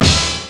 DEF HIT.wav